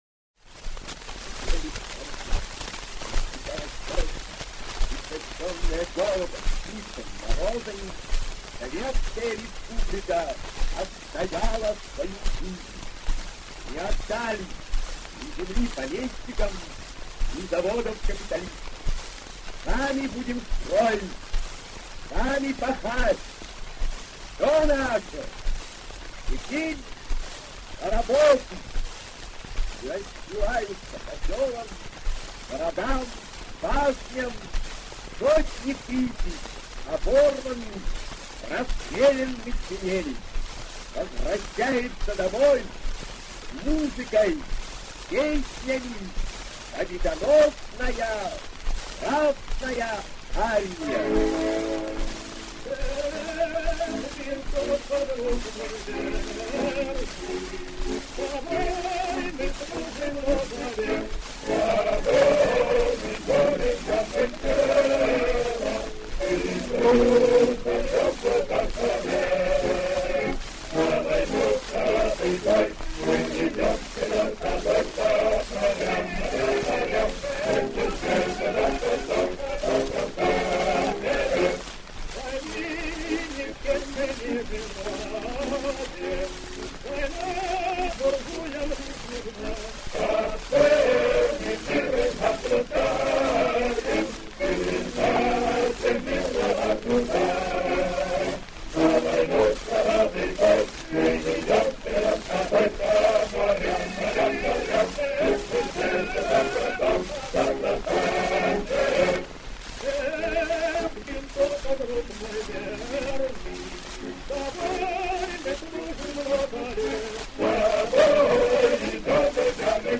Исполнение с декламацией